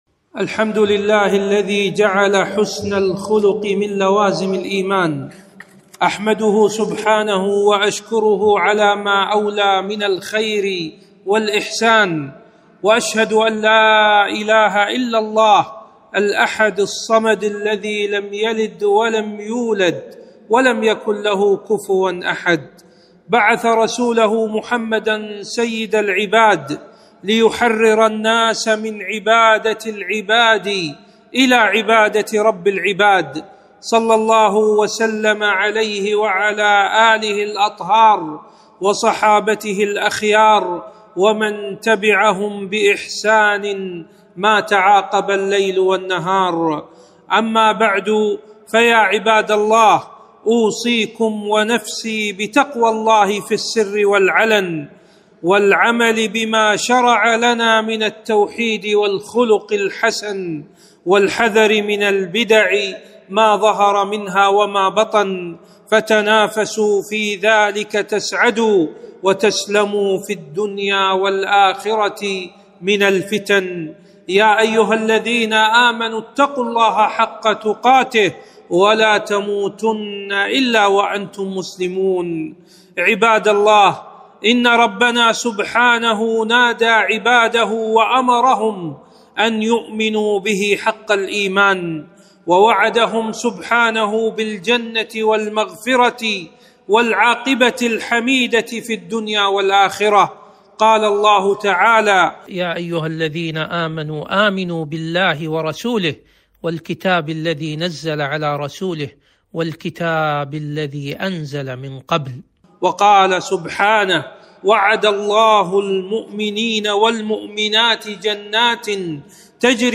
خطبة - أثر العقيدة والإيمان على الأخلاق